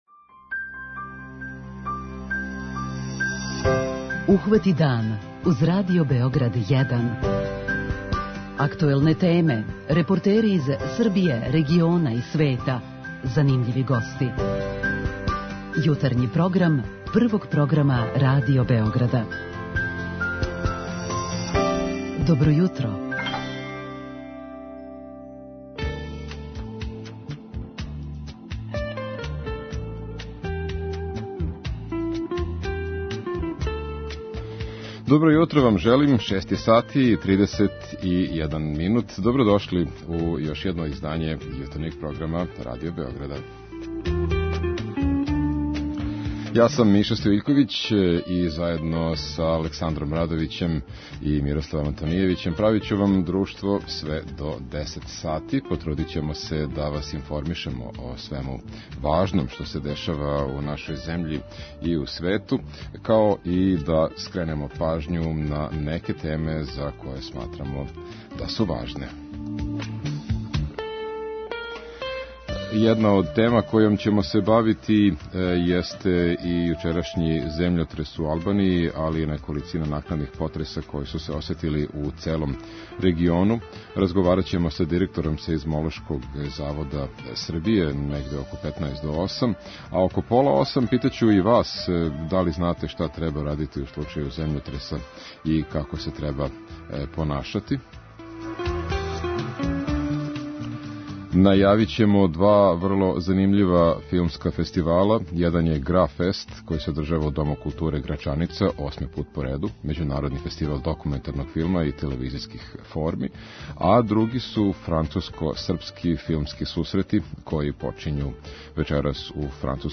преузми : 37.75 MB Ухвати дан Autor: Група аутора Јутарњи програм Радио Београда 1!